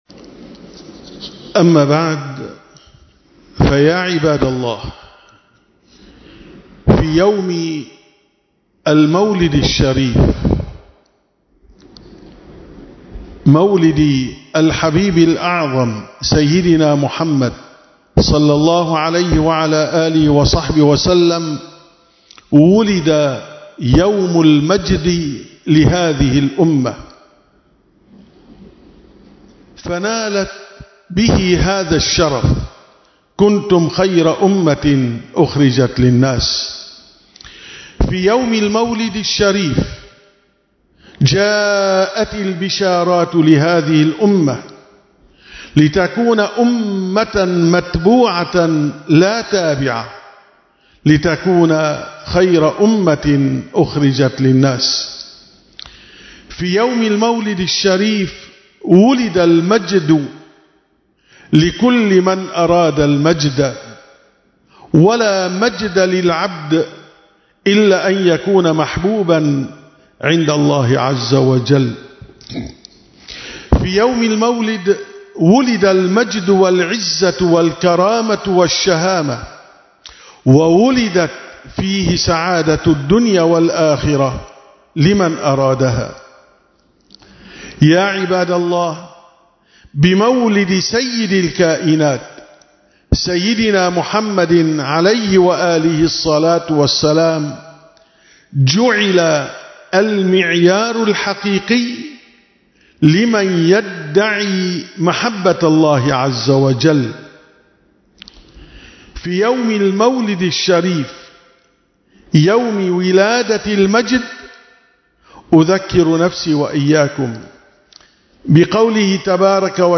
954ـ خطبة الجمعة: يوم المولد يوم مولد المجد